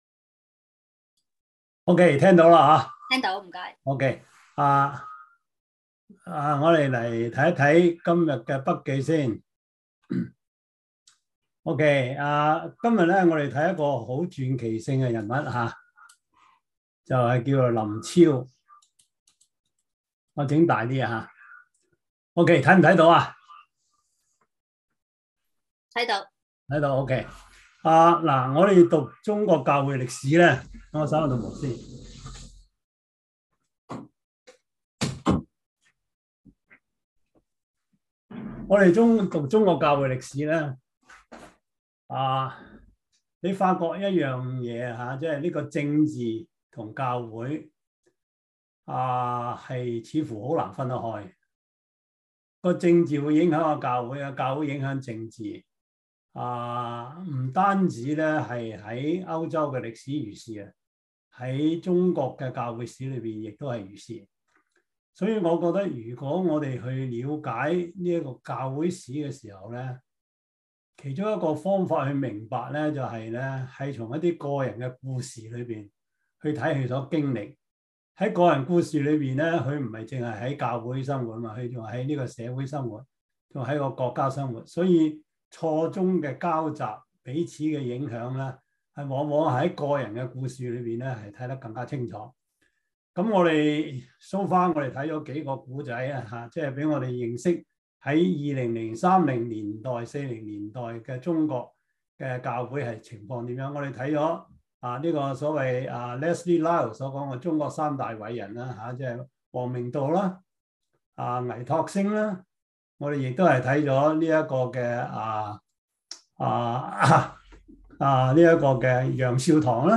Service Type: 中文主日學 中國教會史 – 第二季 第二十九課: 中國教會發展(1927-1937) “如同雲彩中的見證人” 林昭, 師達能 Topics